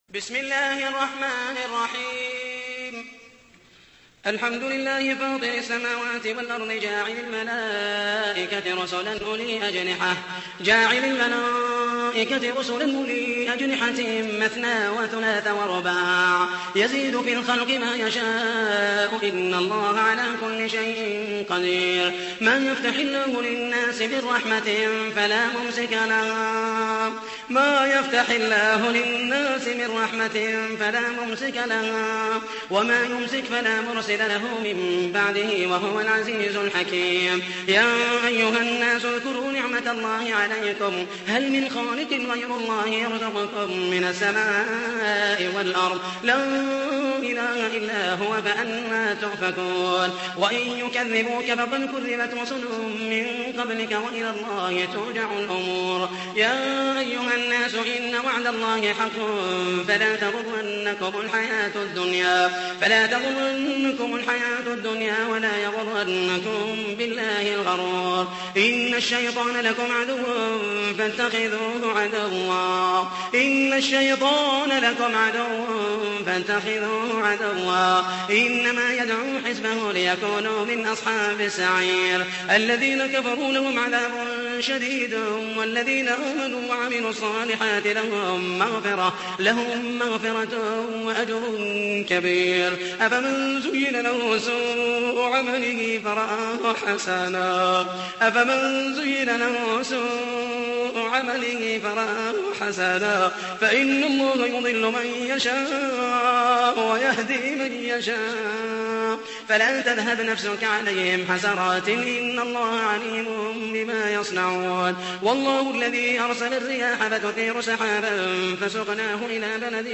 تحميل : 35. سورة فاطر / القارئ محمد المحيسني / القرآن الكريم / موقع يا حسين